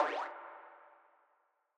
[Snr] Virus3-weird.wav